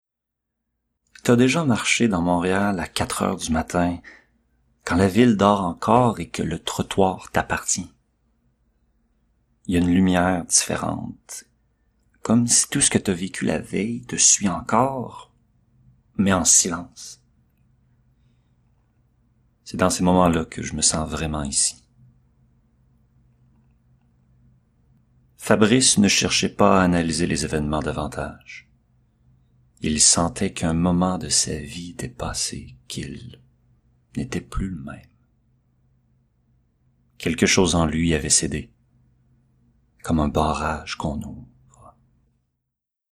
Âge naturelle de la voix Adulte
Langues parlées Français: Québécois naturel à Québécois soutenu
Timbre Grave
Composition vocale Posé - Chaleureux - Sobre
Lecture roman - Sobre, mystérieux, Québécois familier /
Livre audio / Fictif 2025 0:43 1 Mo